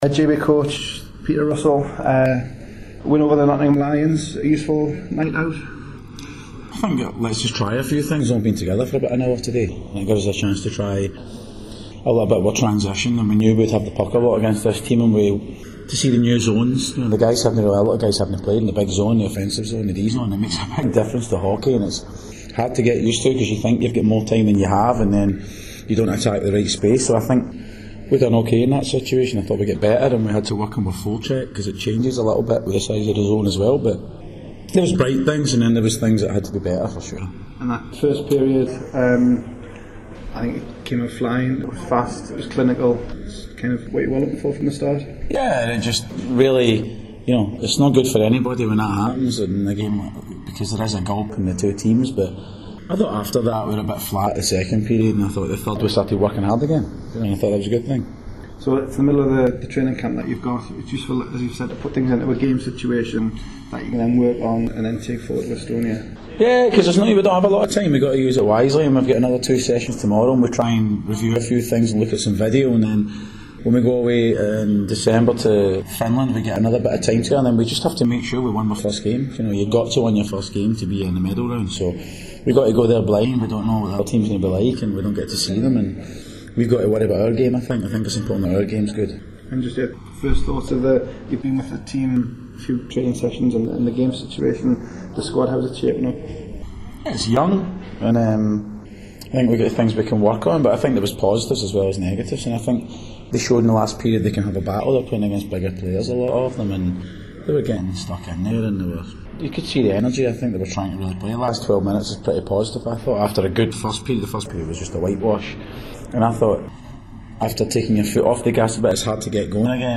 post game